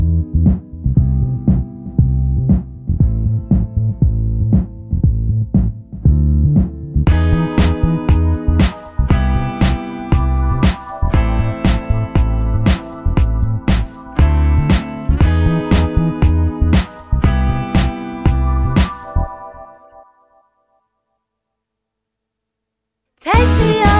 No Rapper R'n'B / Hip Hop 4:10 Buy £1.50